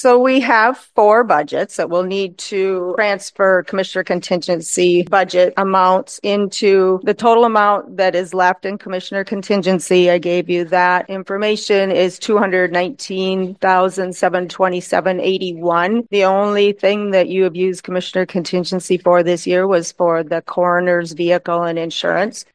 WATERTOWN, S.D.(KXLG)- During this week’s Codington County Commissioners meeting, a series of motions were approved to transfer $70,500 from the Commissioners Contingency Fund to various budgets to make them “whole.”
Codington County Auditor Brenda Hanten explains more to the Commissioners.